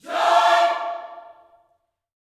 JOY CHORD6.wav